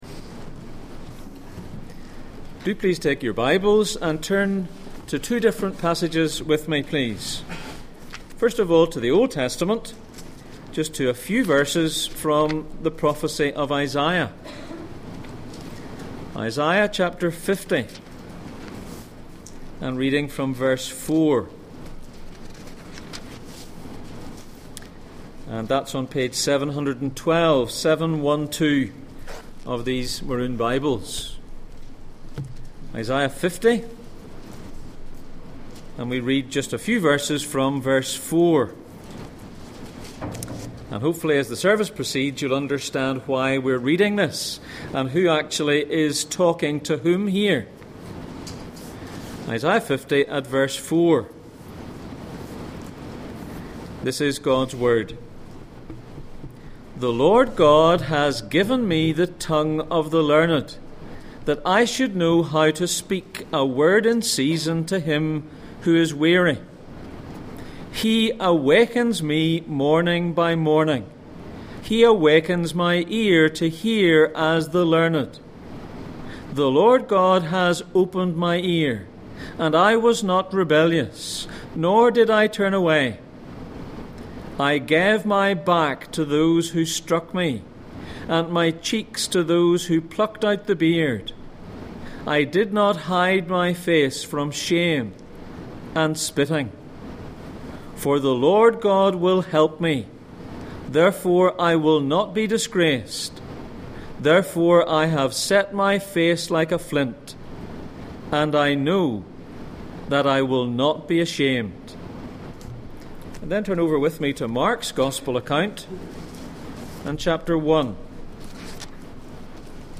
Passage: Mark 1:35-39, Isaiah 50:4-9, Mark 6:46, Mark 14:38 Service Type: Sunday Morning